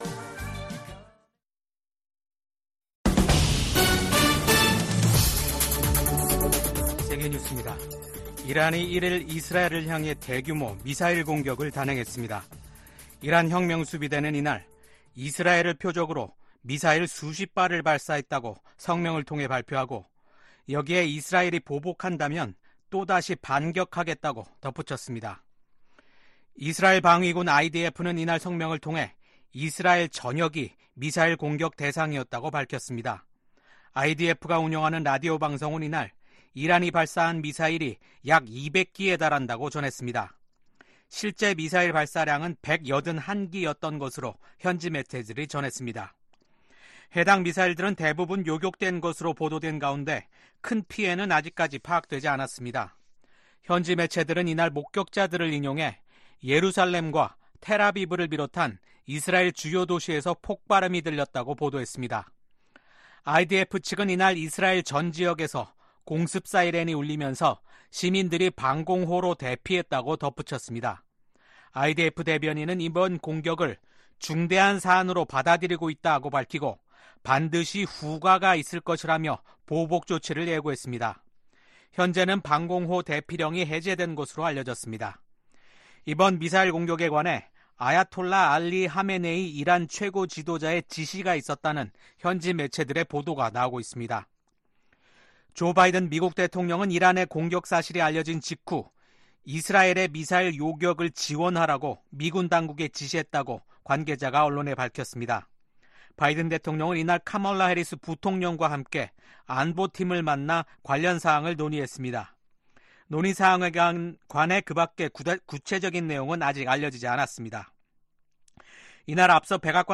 VOA 한국어 아침 뉴스 프로그램 '워싱턴 뉴스 광장' 2024년 10월 2일 방송입니다. 윤석열 한국 대통령은 북한이 핵무기를 사용하려 한다면 정권 종말을 맞게 될 것이라고 경고했습니다. 김성 유엔주재 북한 대사가 북한의 핵무기는 자위권을 위한 수단이며 미국과 핵 문제를 놓고 협상하지 않겠다고 밝혔습니다.